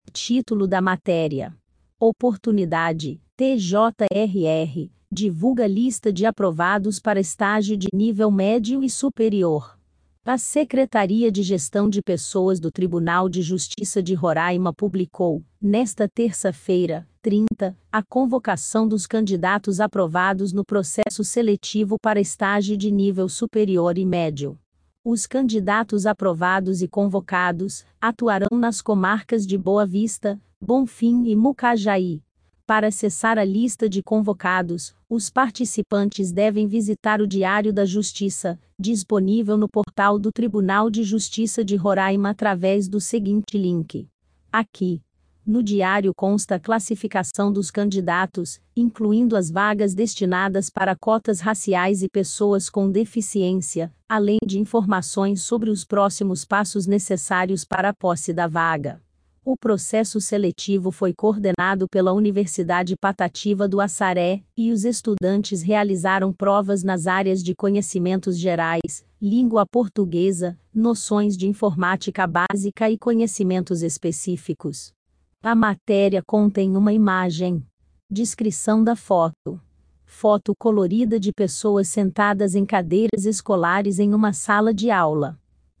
Oportunidade_IA.mp3